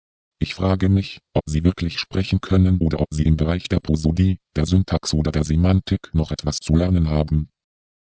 Bsp10 nur mit Akzentkommandos,